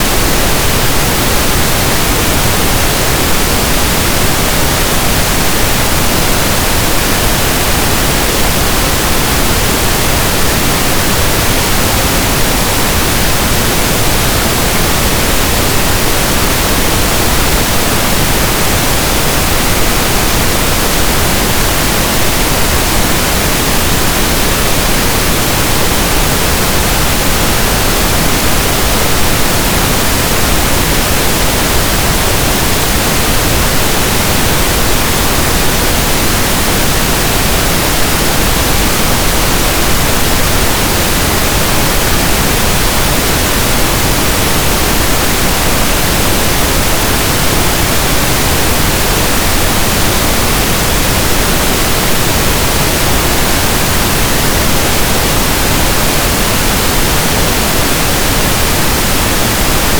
pinkNoiseWav.wav